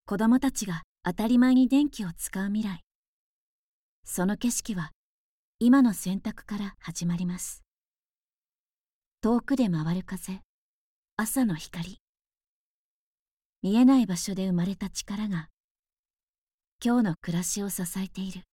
中低音～低音の響く声が特徴です。透明感のあるナレーションや、倍音を活かした語りを得意としています。
低い声の、堂々とした